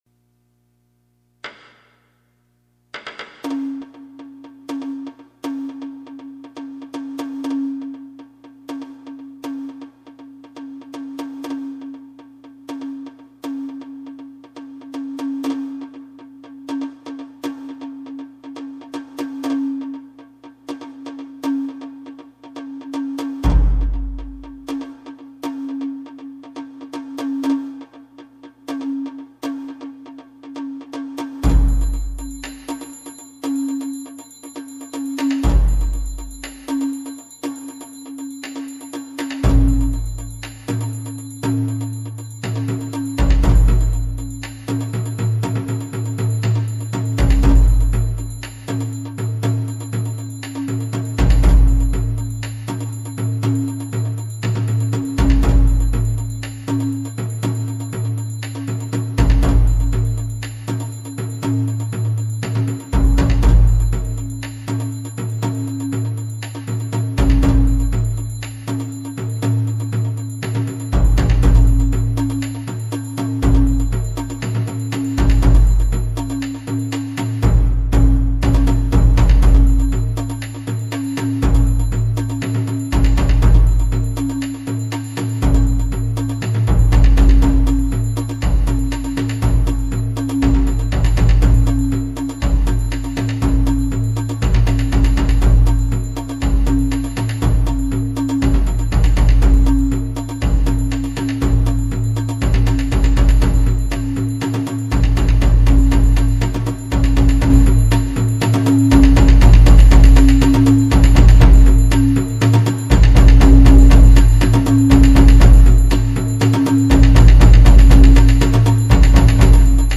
使用楽器　　大太鼓・中太鼓・締太鼓・当たり鉦　（陶製の太鼓）
この曲は全て地場産業である陶器を胴とした太鼓を使用します。
木とは違った鳴りと響き、一風へんてこな打パターンが印象に残ると思います。